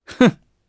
Roi-Vox-Laugh_jp.wav